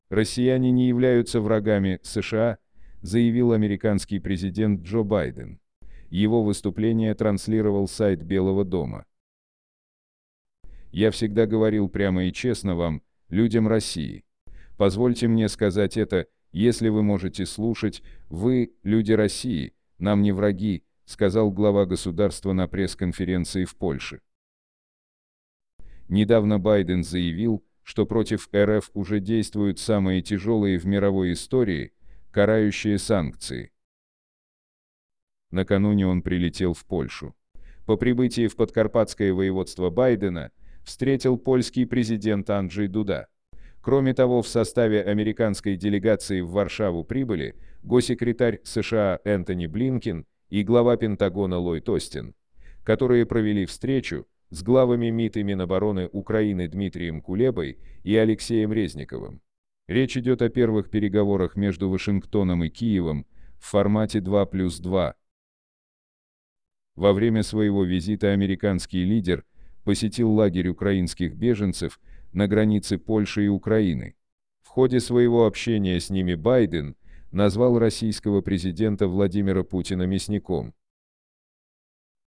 Россияне не являются врагами США, заявил американский президент Джо Байден. Его выступление транслировал сайт Белого дома.